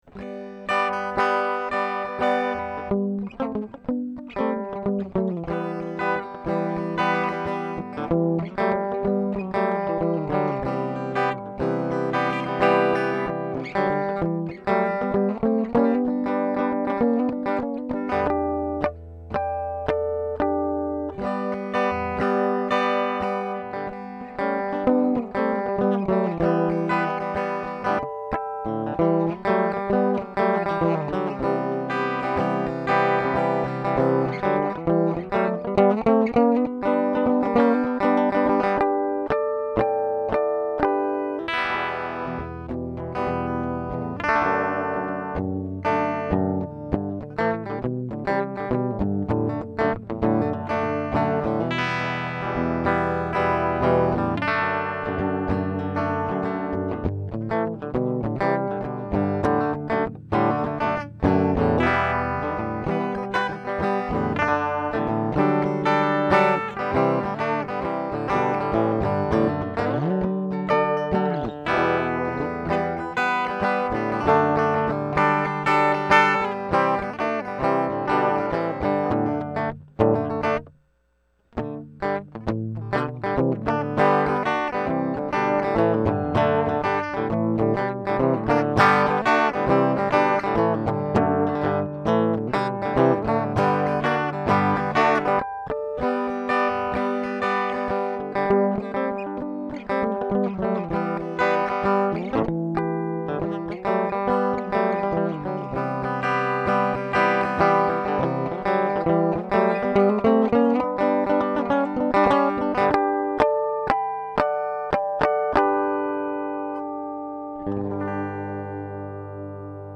Here are 7 quick, 1-take MP3 sound files of myself playing this guitar, to give you an idea of what to expect. The guitar has great tone, sustain, and body, and also tonal variety, as the P90s add just the right amount of bite in the bridge position. The clean MP3 file is straight signal into the DI input on the Presonus ADL600 tube preamp, and the distorted files use a Doc Scary Overdrive unit, in to the ADL600, and Rosetta 200 convertersion, and MP3s were made in Logic using the stock "Warm Echoes" preset.
(Original, in G)